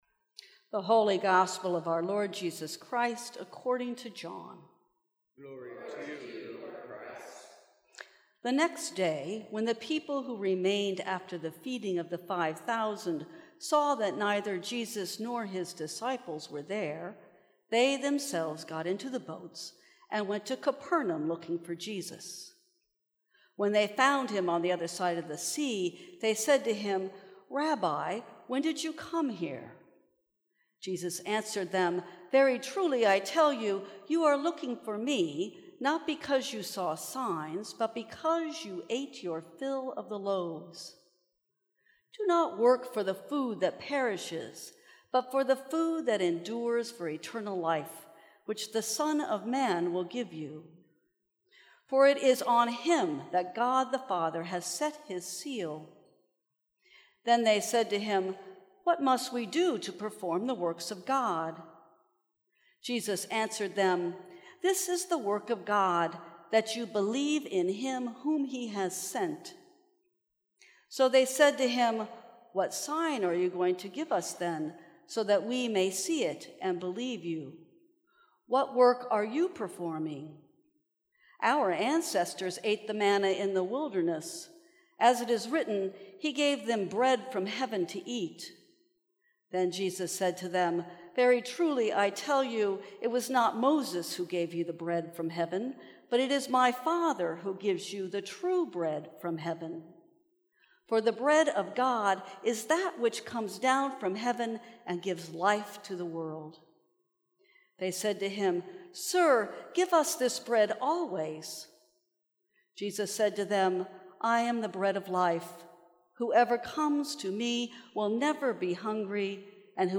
Sermons from St. Cross Episcopal Church Hold On for a Wild Ride Aug 06 2018 | 00:10:31 1x 00:00 / 00:10:31 Subscribe Share Apple Podcasts Spotify Overcast RSS Feed Share Link Embed